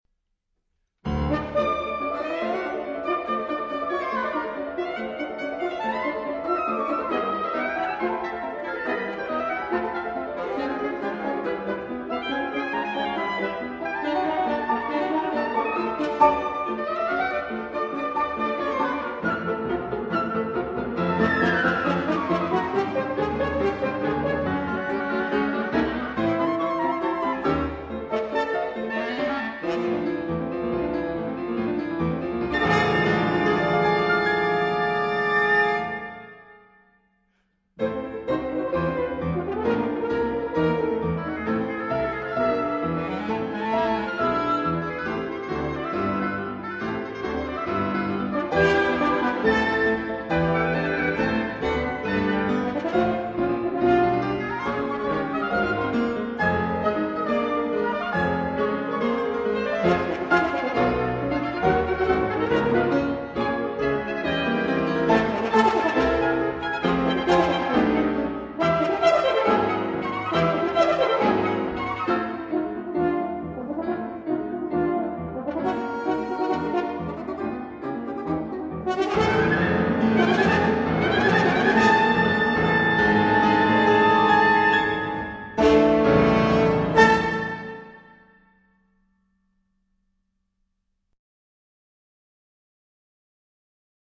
cd-aufnahmen